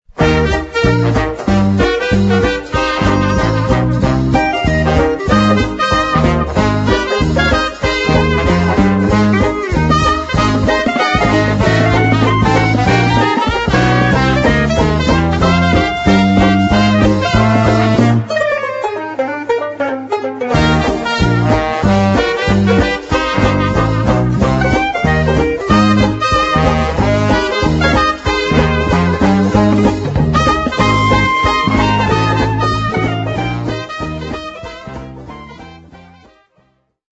funny medium instr.